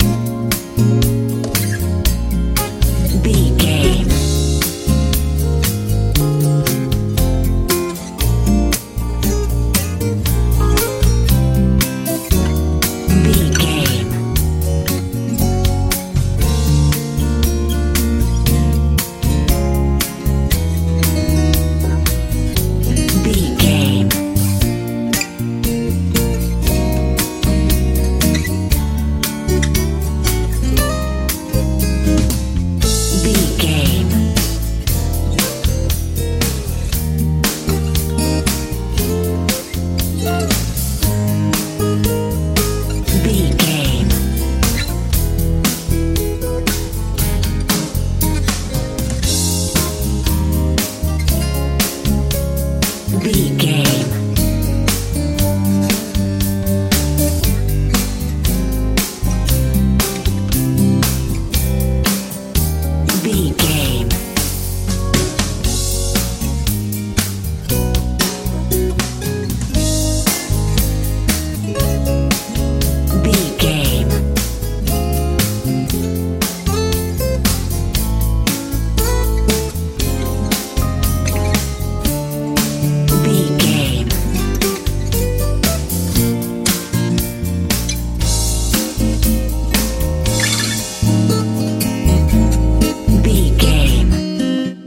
easy rock
Aeolian/Minor
B♭
mellow
acoustic guitar
electric piano
bass guitar
drums
tranquil
soothing
smooth
relaxed